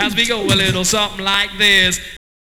DJP_VOX_ (2).wav